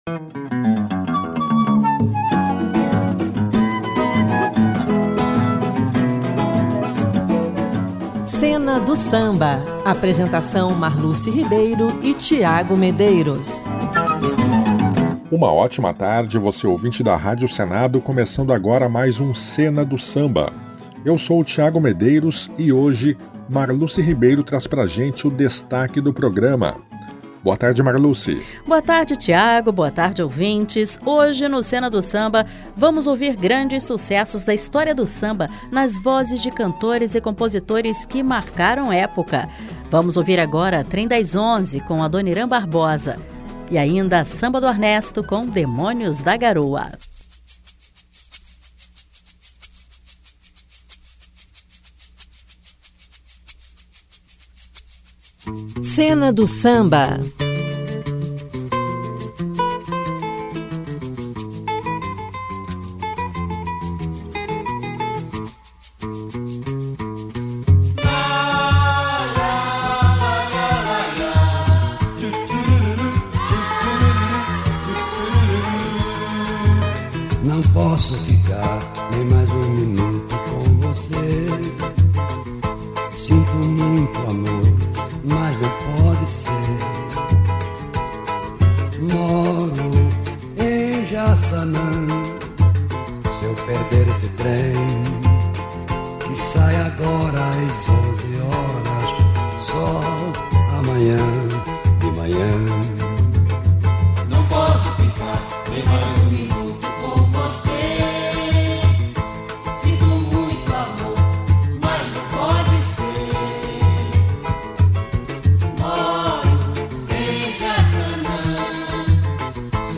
sucessos de vários artistas que marcaram a história do samba